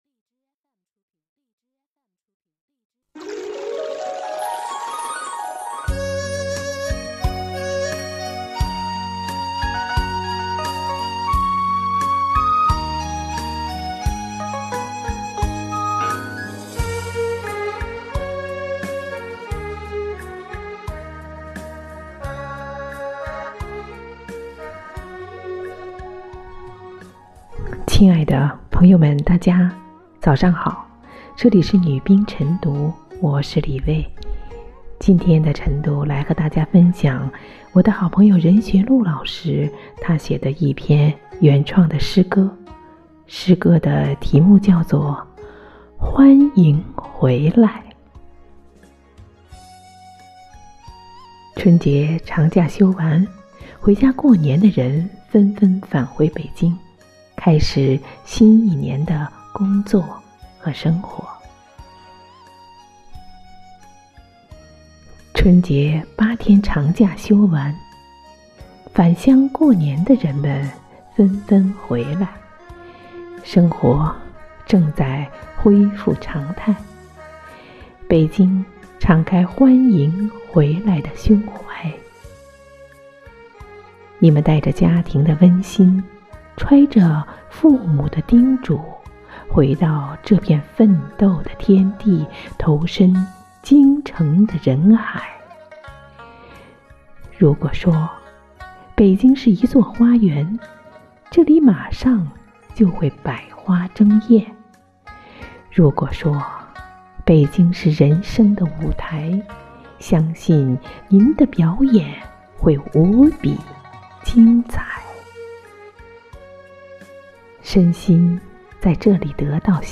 女兵诵读